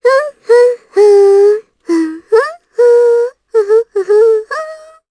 Ophelia-Vox_Hum_jp.wav